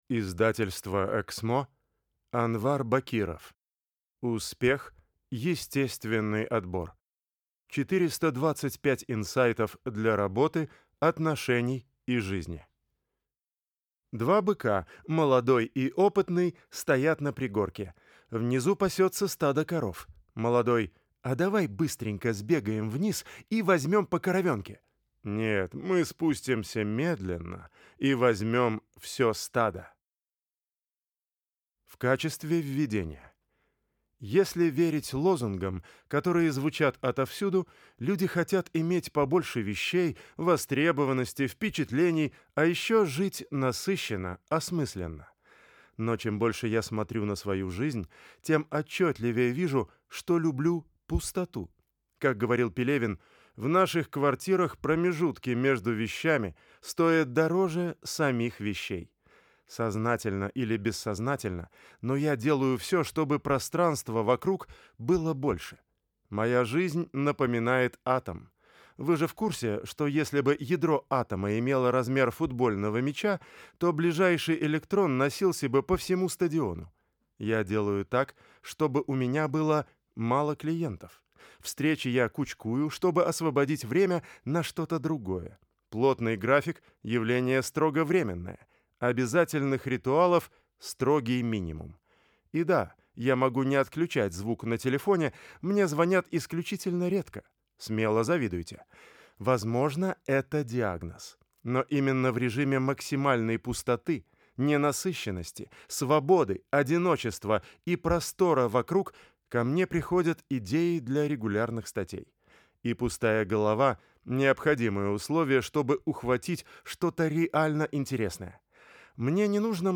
Аудиокнига Успех. Естественный отбор. 425 инсайтов для работы, отношений и жизни | Библиотека аудиокниг